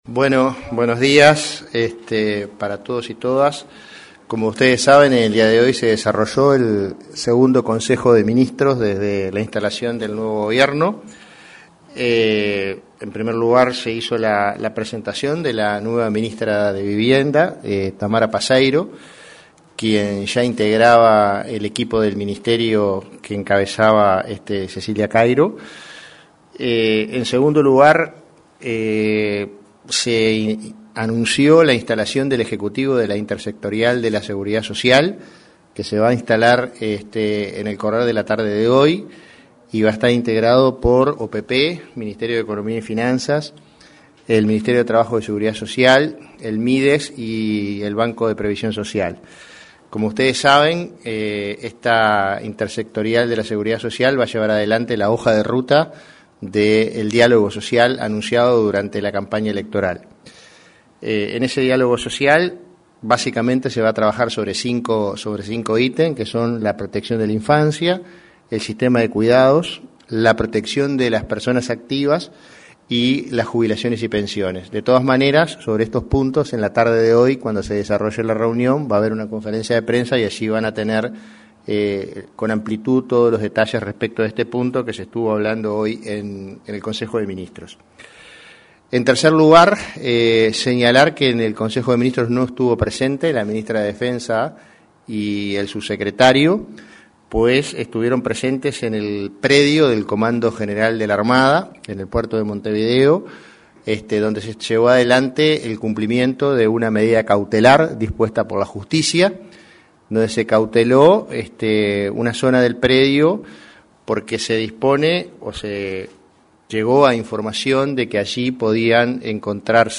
Conferencia de prensa tras Consejo de Ministros
Este martes 22 de abril, luego del Consejo de Ministros; el prosecretario de Presidencia de la República, Jorge Díaz, informó respecto a los temas